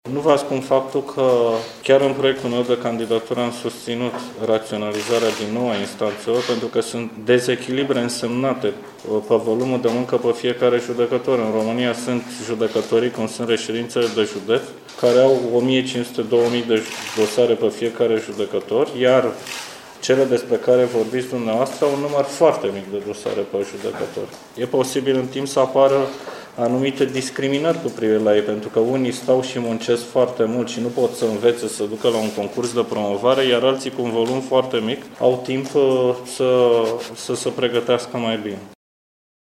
Noul preşedinte al Consiliului Superior al Magistraturii, judecătorul Marius Tudose, a spus, la Timişoara, că va relua în perioada următoare discuţiile pentru eficientizarea instanţelor din România.